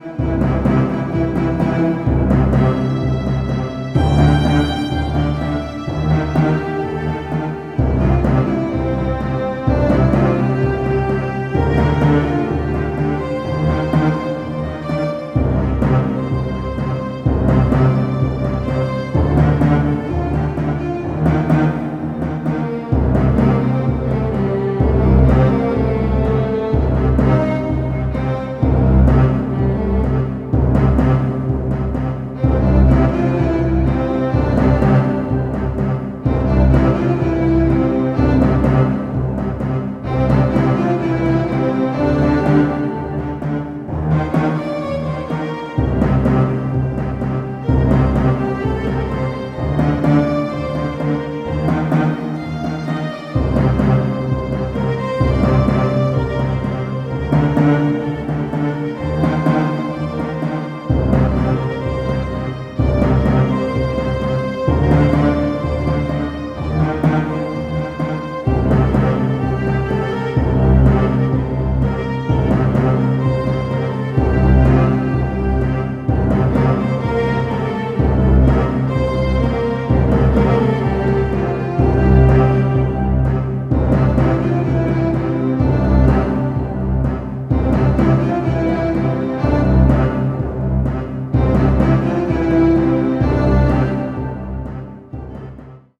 Epic Happy Soundtrack.